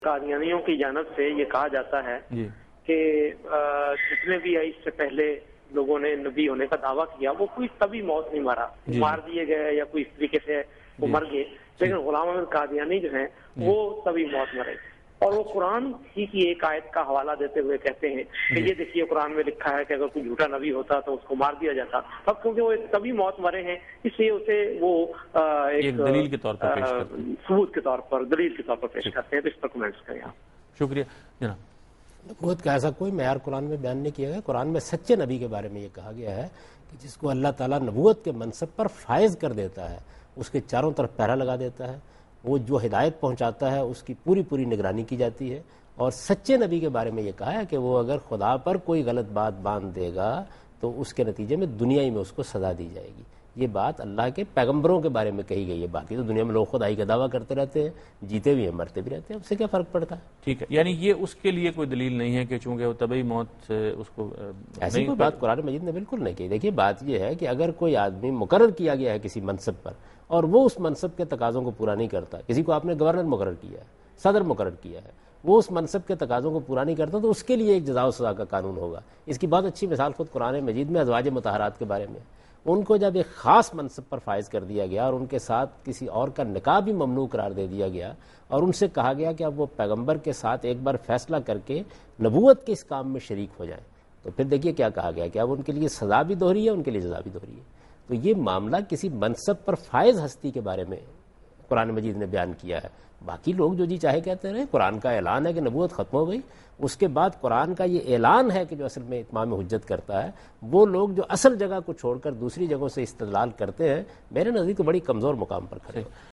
Category: TV Programs / Dunya News / Deen-o-Daanish / Questions_Answers /
Answer to a Question by Javed Ahmad Ghamidi during a talk show "Deen o Danish" on Duny News TV